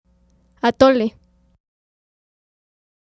Atole (Spanish: [aˈtole]